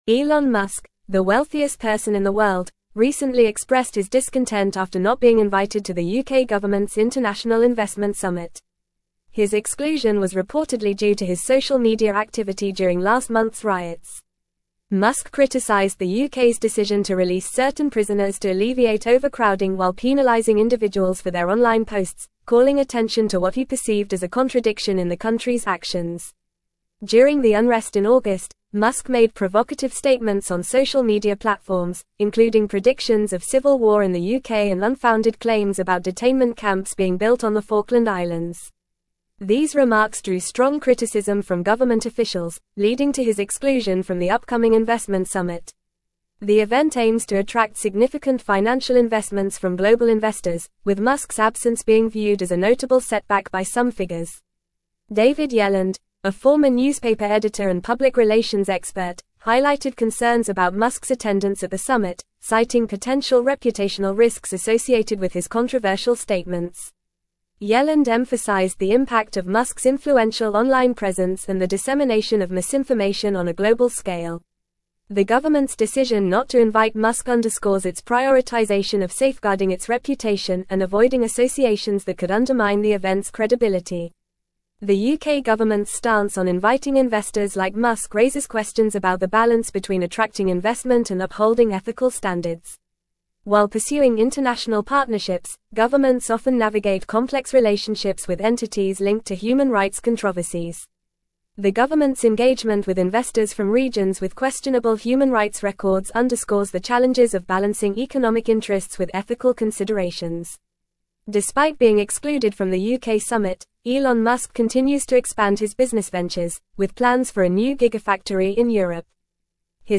Fast
English-Newsroom-Advanced-FAST-Reading-Elon-Musk-Excluded-from-UK-Investment-Summit-Over-Posts.mp3